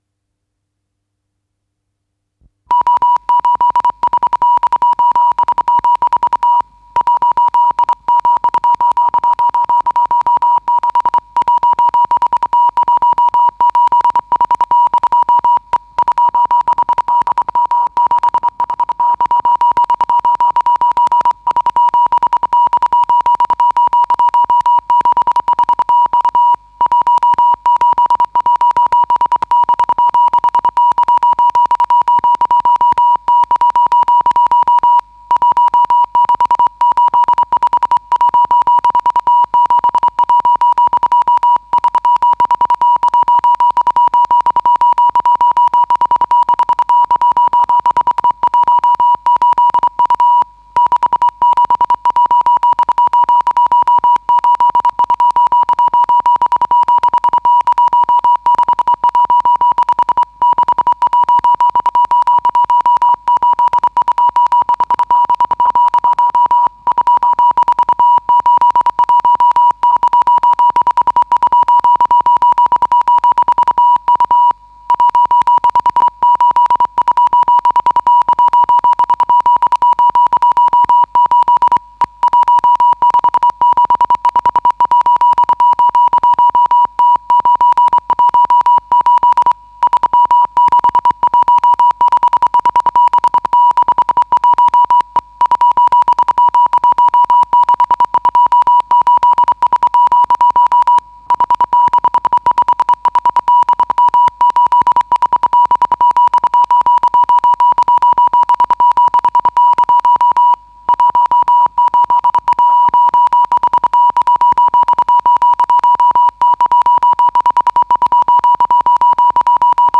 с профессиональной магнитной ленты
Запись Н-699 «Азбука морзе»#598
РедакцияШумовая
ВариантМоно